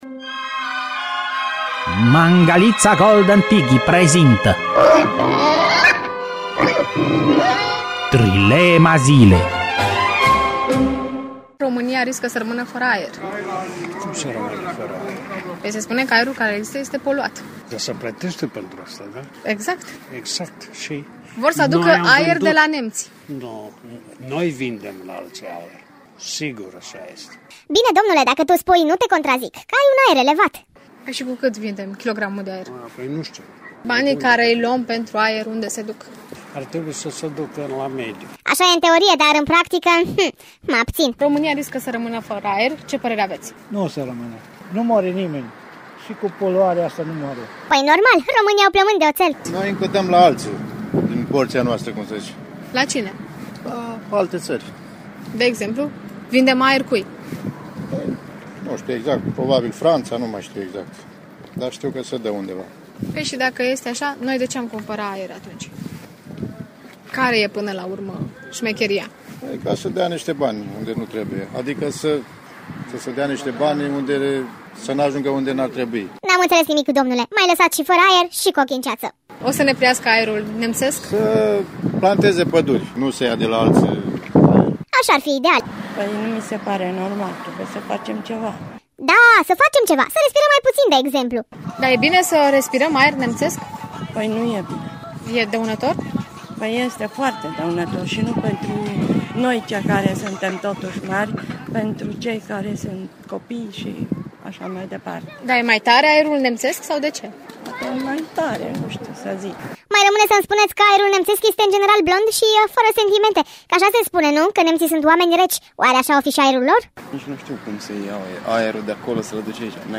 au stat de vorbă cu câteva persoane şi a aflat răspunsul la această întrebare.